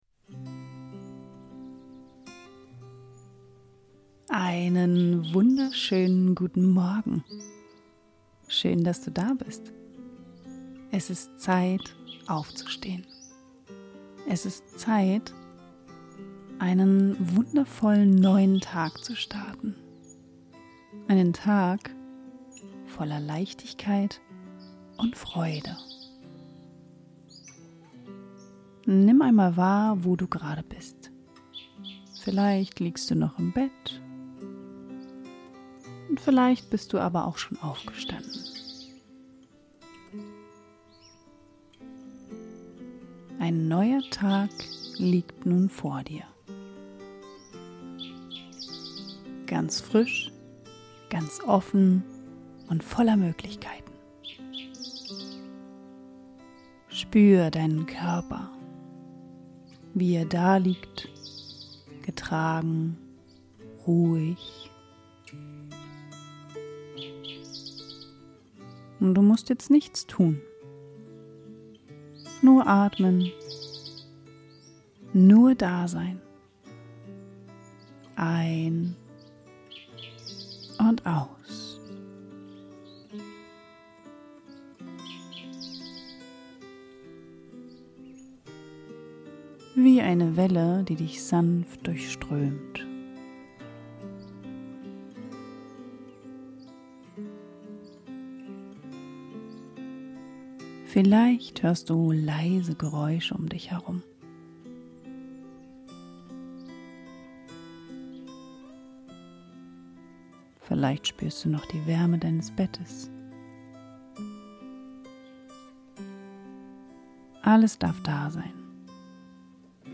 #49 -Atemmeditation - Dein Morgenritual für Lebensfreude und Fokus ~ Körperweg Podcast